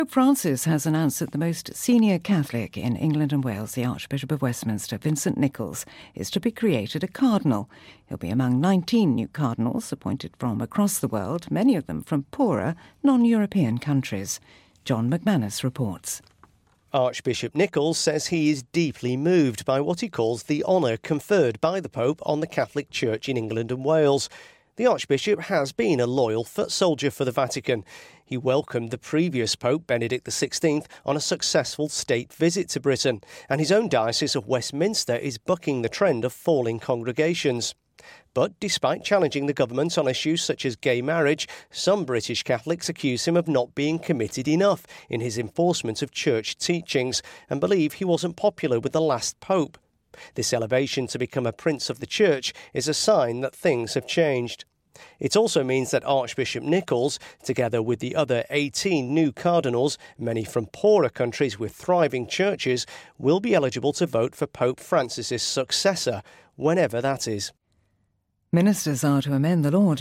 BBC Radio 4 report.